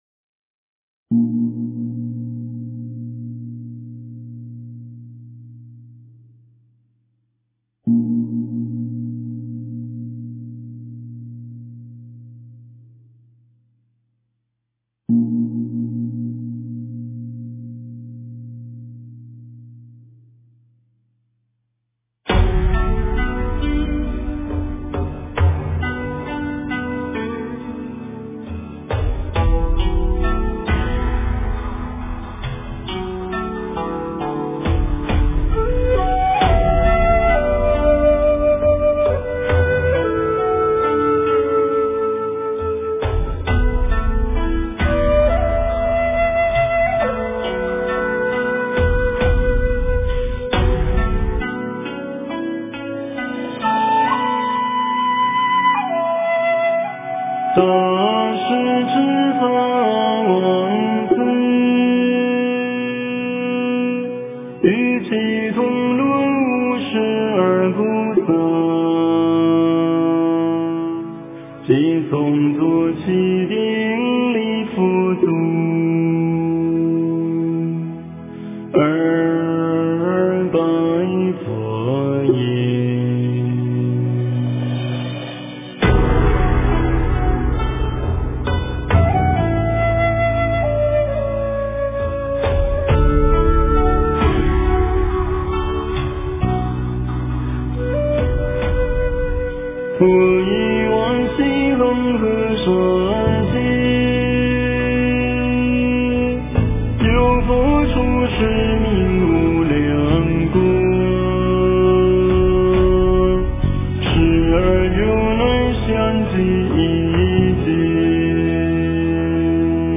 诵经
佛音 诵经 佛教音乐 返回列表 上一篇： 早课1-楞严咒+大悲咒 下一篇： 金刚经 相关文章 Asian Rhapsody亚洲狂想曲--禅定音乐 Asian Rhapsody亚洲狂想曲--禅定音乐...